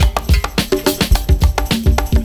106PERCS05.wav